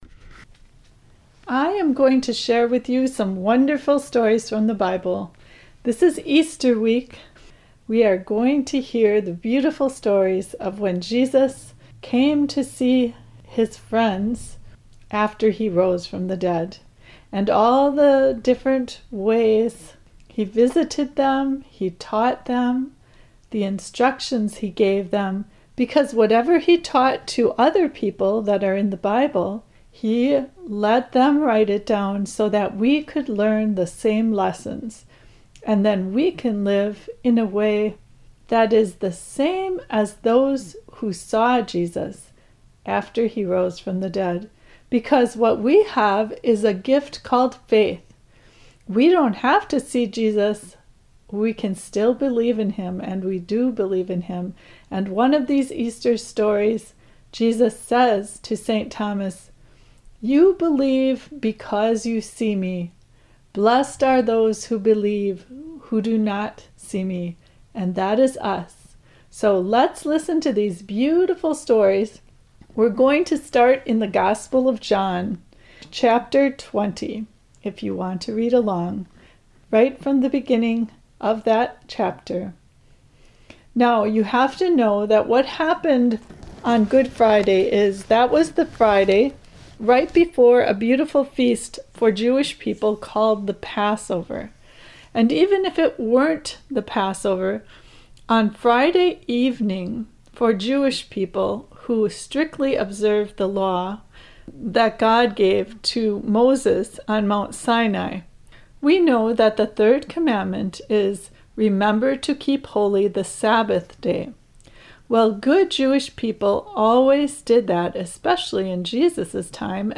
(Just audio stories) I will bring them up to a new post so that you and your children can listen to them again. They present the Easter stories with simple language so that children can listen and understand. I also included questions the children can answer after the story is finished.